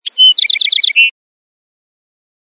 Bird Call.mp3